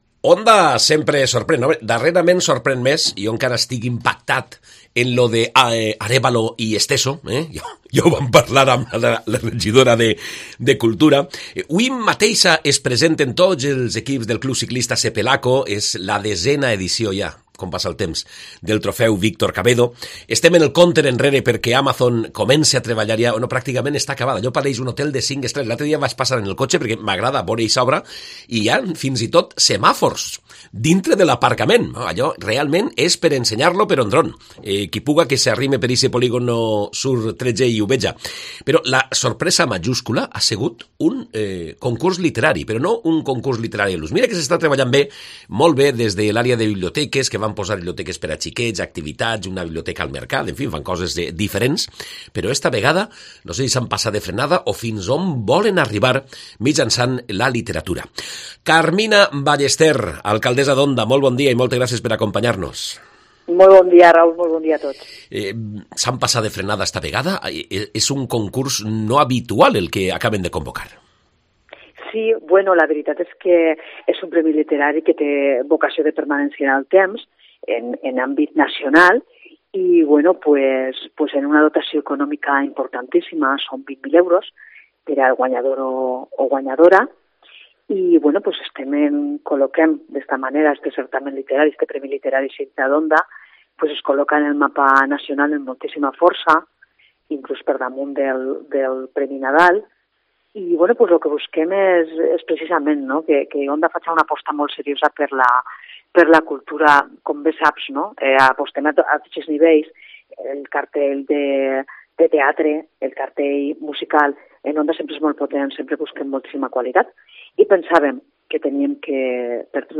Castellón Entrevista Onda anuncia un premio literario Onda anuncia un premio literario. Además, su alcaldesa, Carmina Ballester repasa la actualidad municipal y política en su formación, el Partido Popular